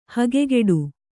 ♪ hakkugaṭṭu